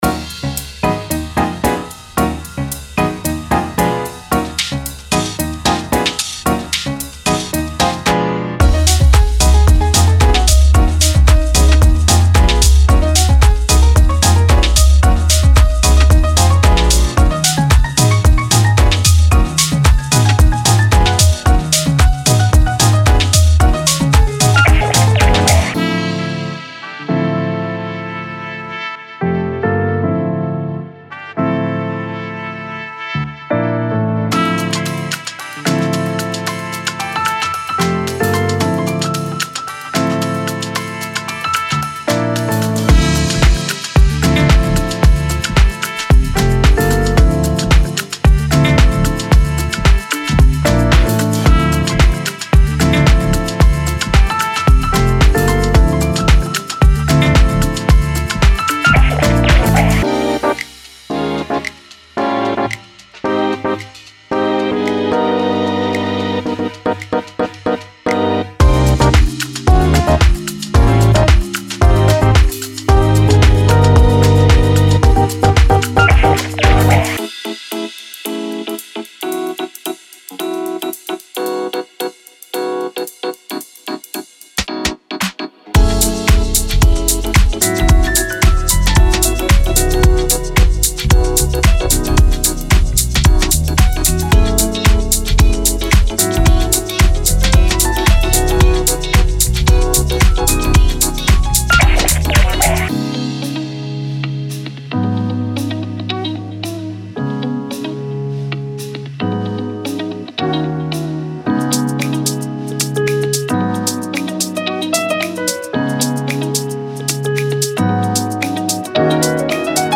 Genre:Deep House
スモーキーな深夜のジャズとソウルフルなディープハウスが融合する、ベルベットのように照らされた世界に滑り込みましょう。
デモサウンドはコチラ↓